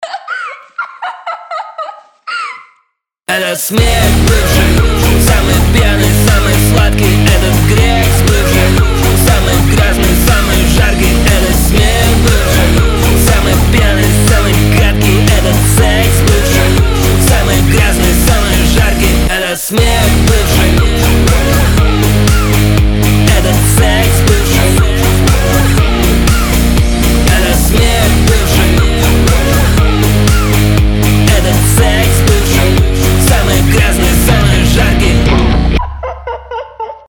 громкие
Драйвовые
веселые
Alternative Rock
быстрые
Рэп-рок